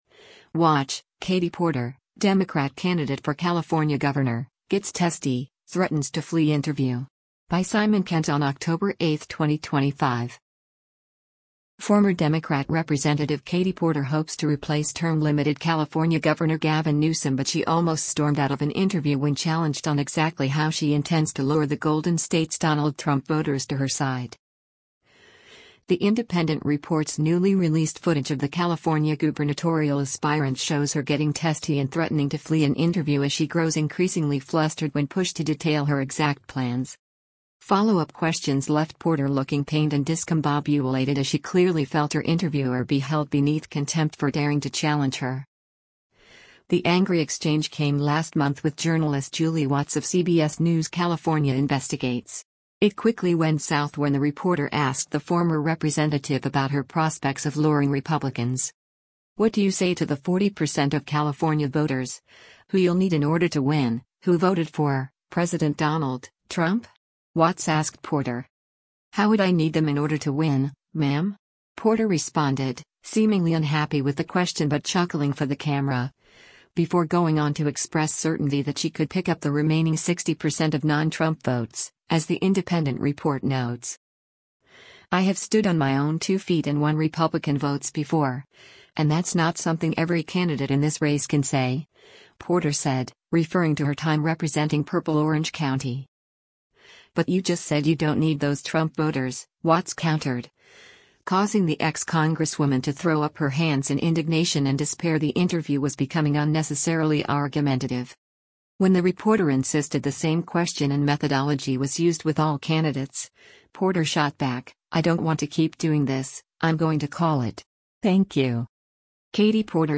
The Independent reports newly released footage of the California gubernatorial aspirant shows her getting testy and threatening to flee an interview as she grows increasingly flustered when pushed to detail her exact plans.